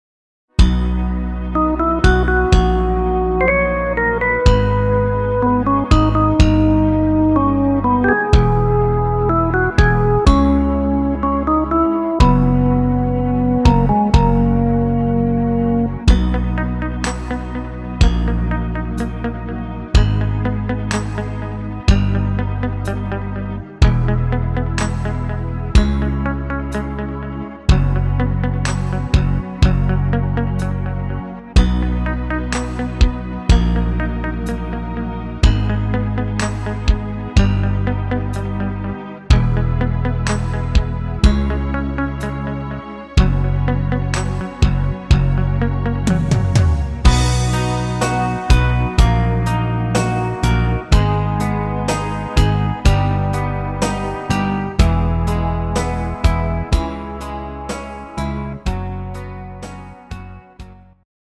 Evergreen aus Frankreich